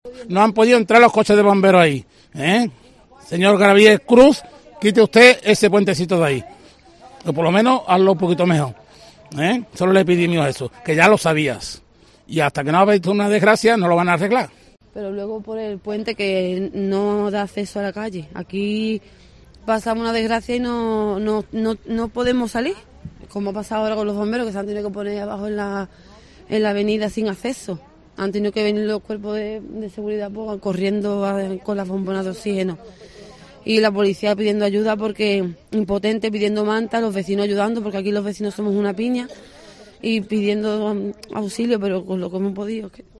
Vecinos de la Hispanidad denunciando la pasarela